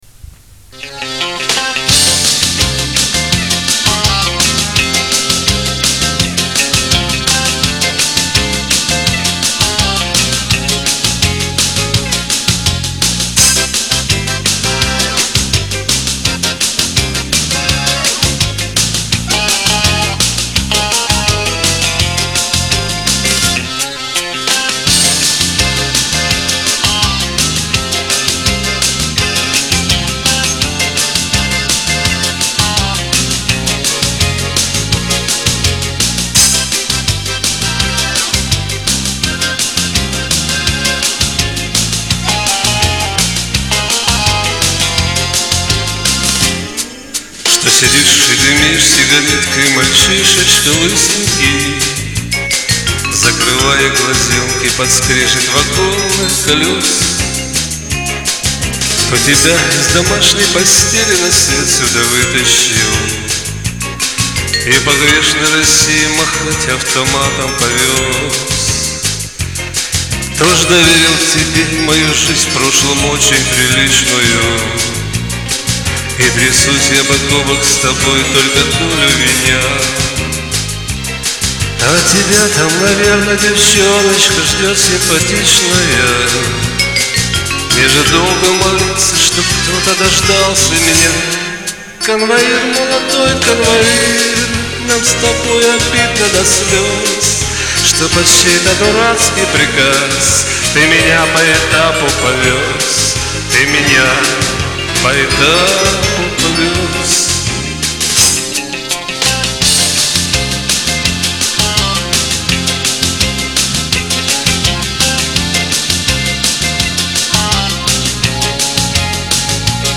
Комментарий инициатора: Спел по своему по мужски.